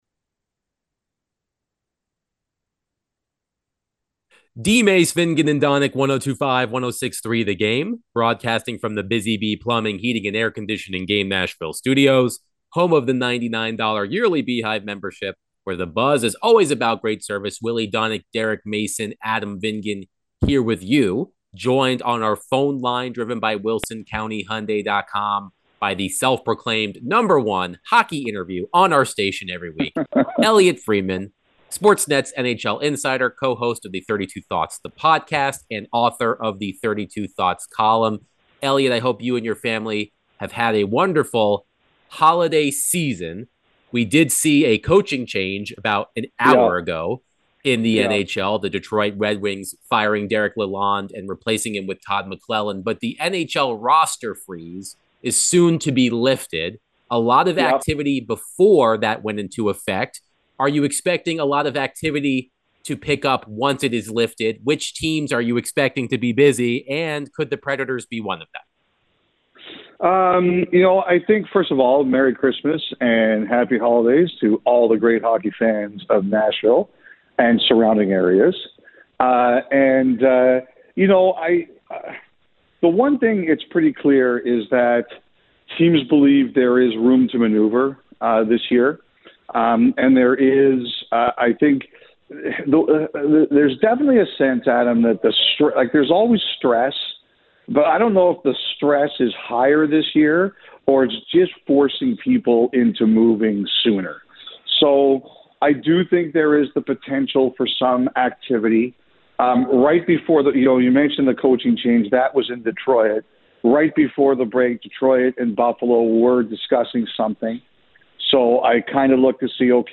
Sports Net NHL Insider Elliotte Friedman joined DVD to discuss the NHL news, Preds and more